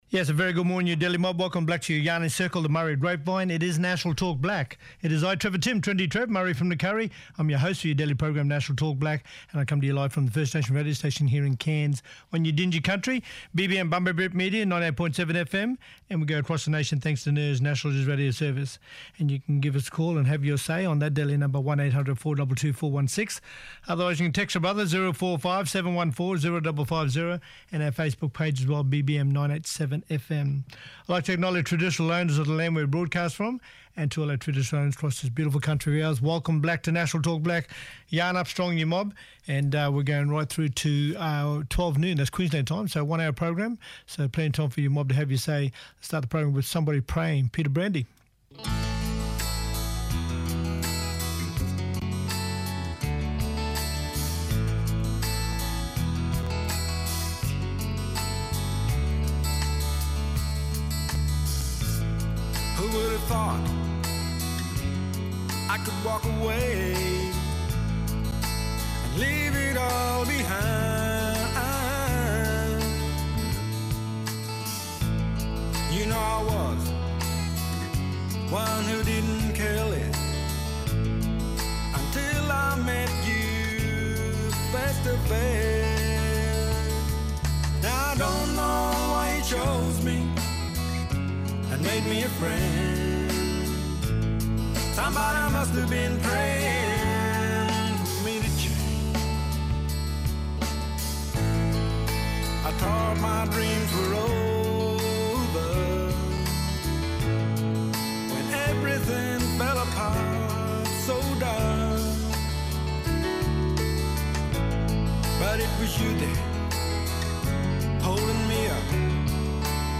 Linda Scott, Australian Local Government Association (ALGA) President, talking about how dedicated domestic violence prevention officers in local government can make our communities safer. Local government has renewed its commitment to preventing violence against women and children, saying it wants to work more closely with other levels of government to drive outcomes.